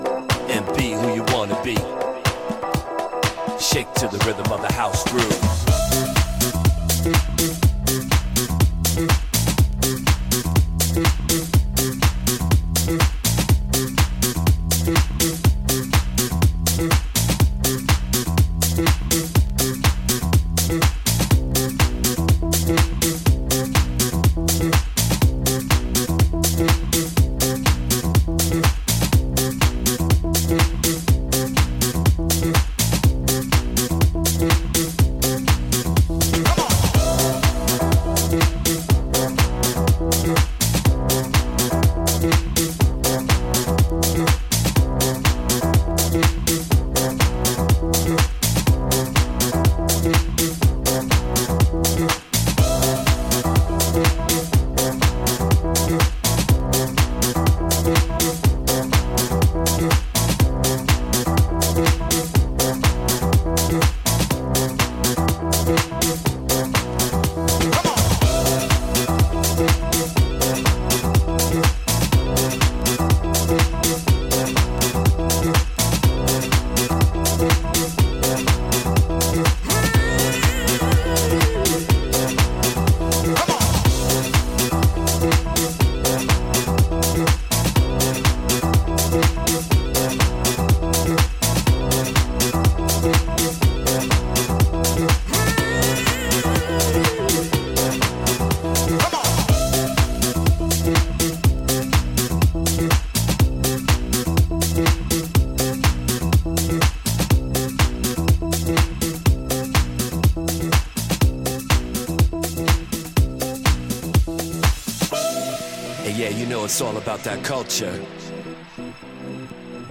ウォーミーでファンキーなハウスを展開していくA面